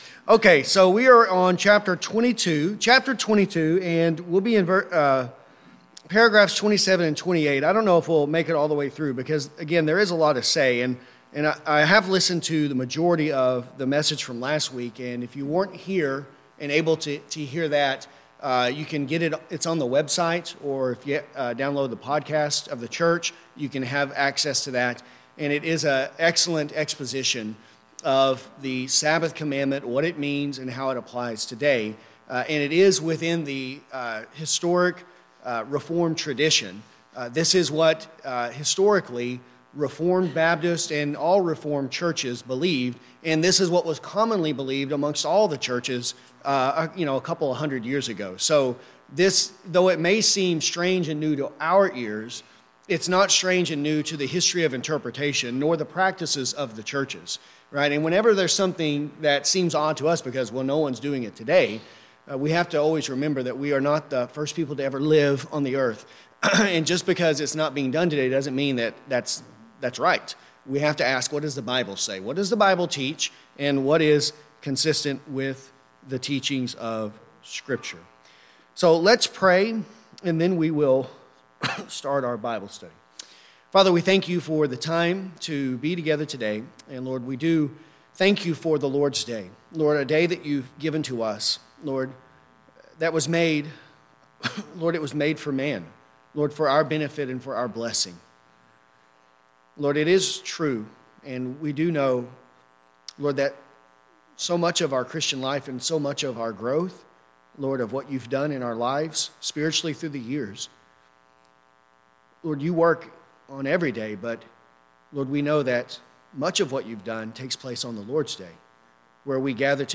Second London Baptist Confession of 1689 Click the link below to download the article written by Robert Murray McCheyne mention in the sermon.